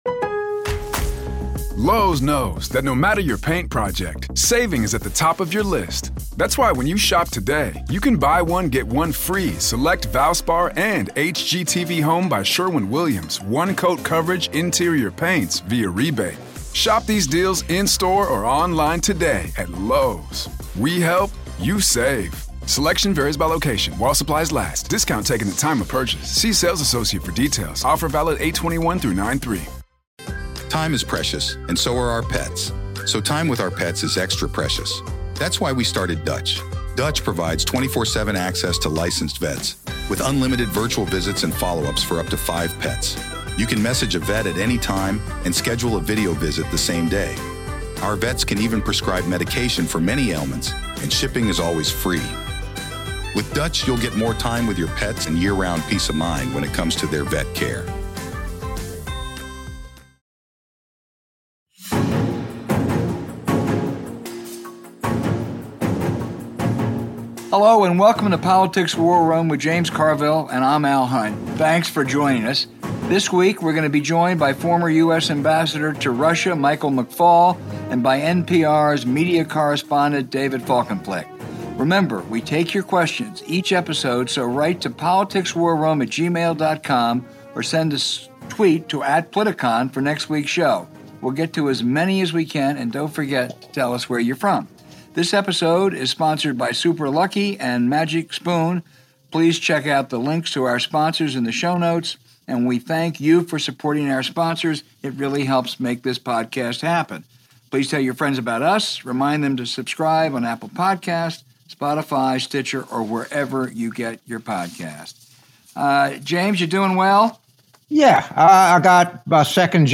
James and Al have on former Ambassador Michael McFaul to fill us in on the latest developments in Russia, with NPR’s David Folkenflik on the shake up on Fox and the other big networks now that Biden is in charge.